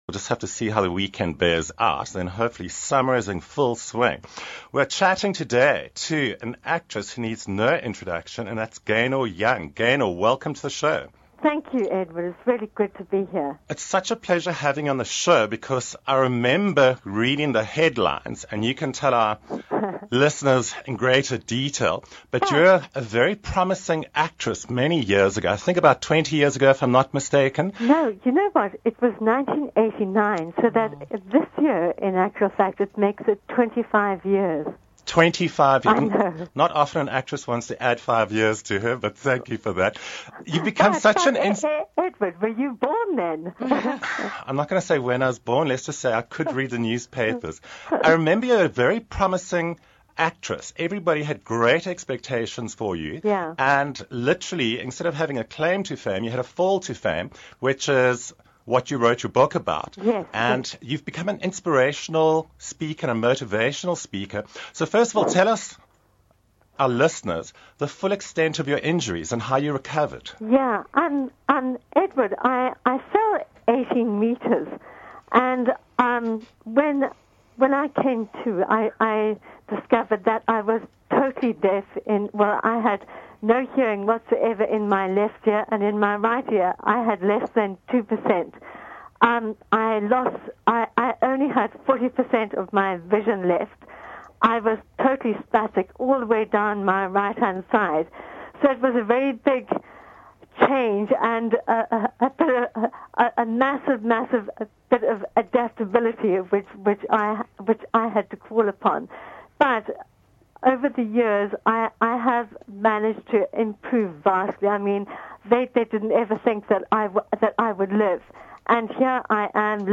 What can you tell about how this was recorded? telephone radio interview